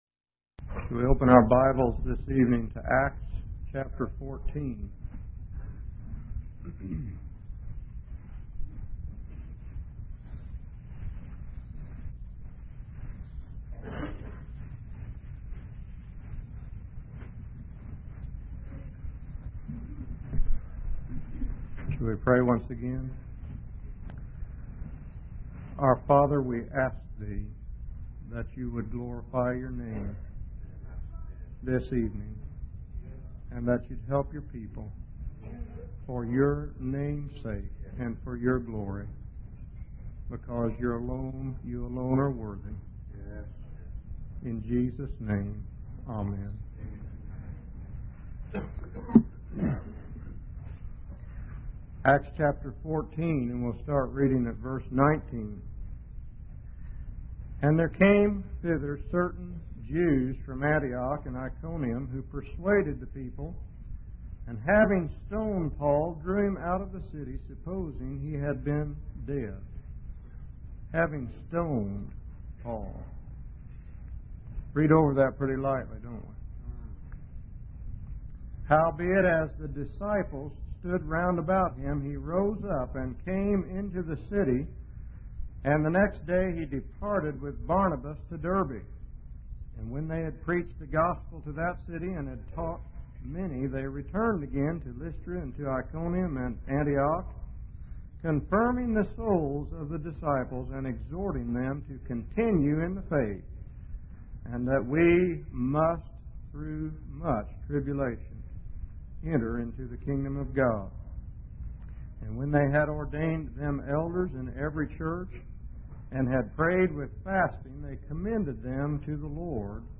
In this sermon, the preacher emphasizes the importance of understanding that believers are valuable and precious to God. He uses three different metaphors - the refining of metal, the pruning of a vine, and the chastening of a son - to illustrate the process of spiritual growth and purification. The preacher highlights the painfulness of this process, comparing it to a blast furnace and emphasizing the need for believers to endure trials and tribulations.